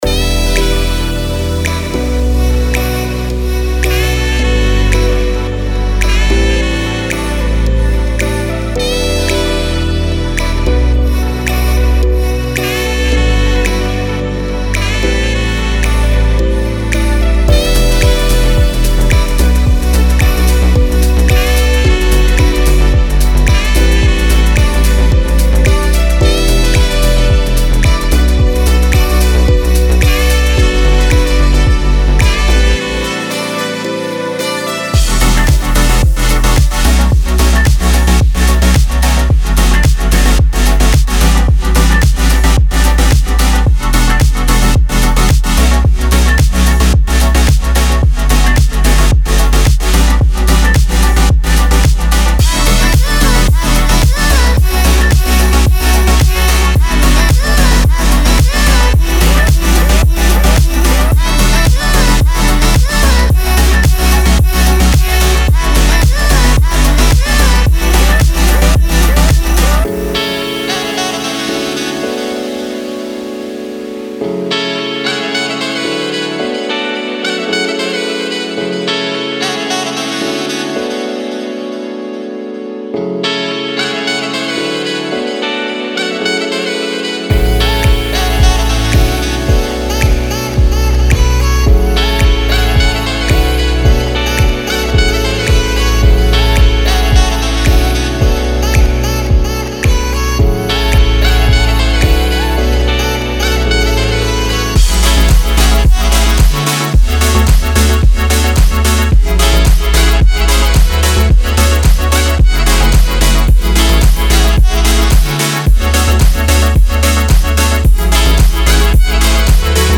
Sweet and melodic future funk sounds.